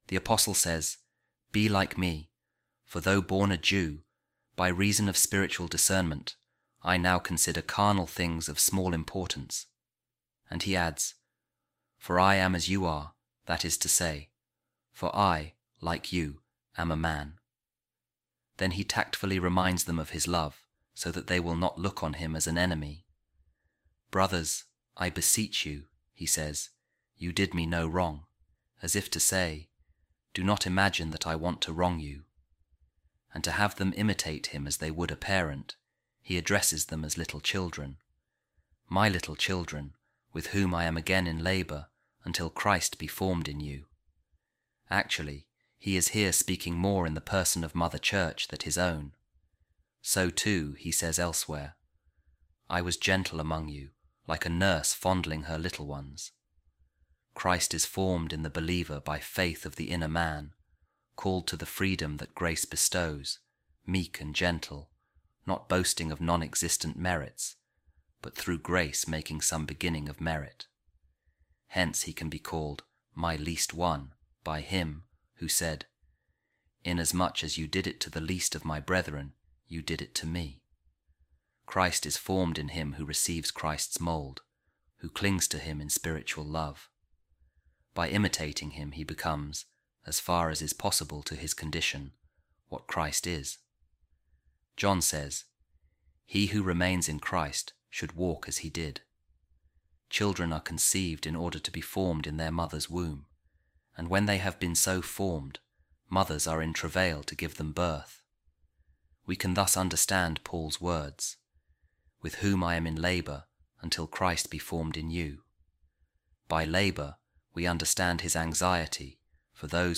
A Reading From Saint Augustine’s Explanation Of The Letter To The Galatians | Till Christ Shall Be Fully Formed In You